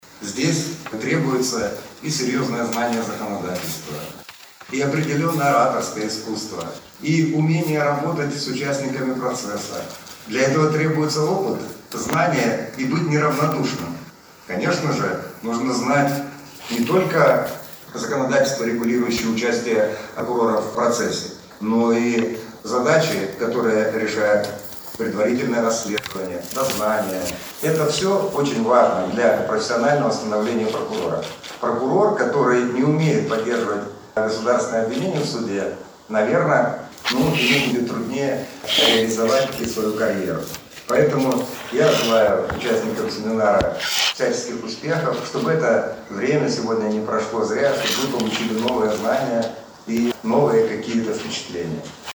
С приветственным словом к гостям обратился прокурор Брестской области Виктор Климов. Он рассказал, какие знания и умения необходимы для профессионального становления прокурора.